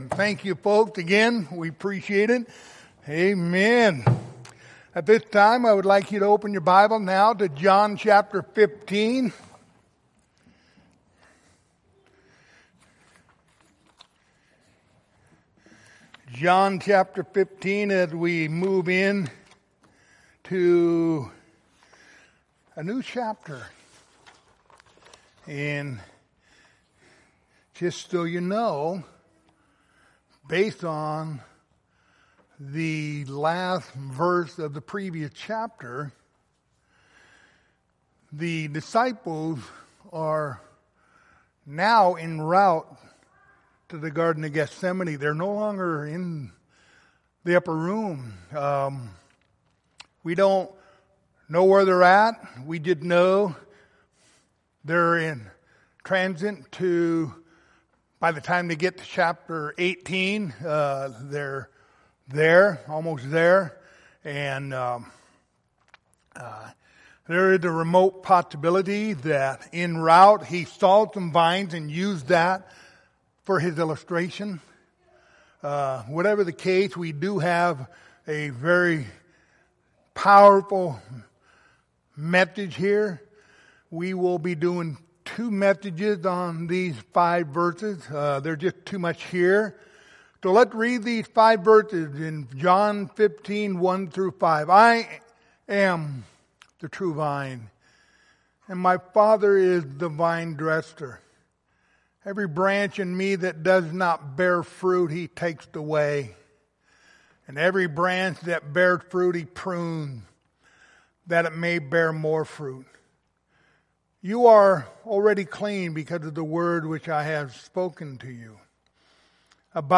Passage: John15:1-5 Service Type: Wednesday Evening